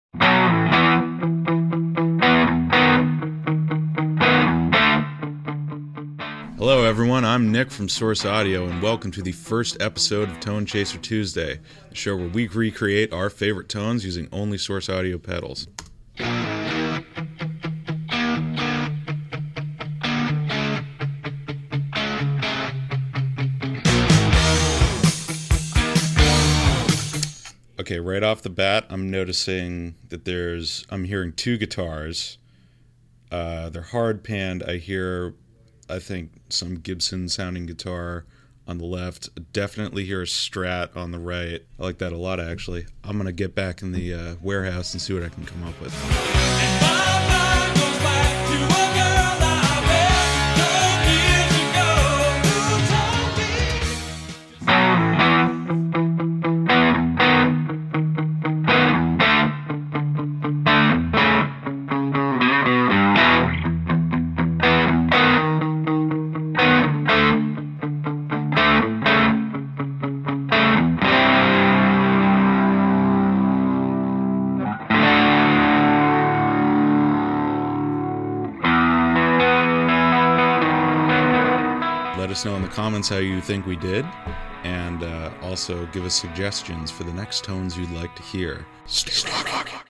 guitar tone